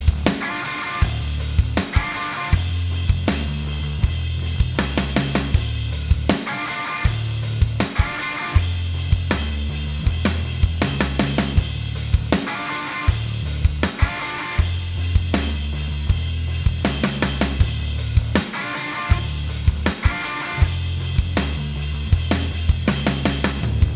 Minus All Guitars Rock 4:06 Buy £1.50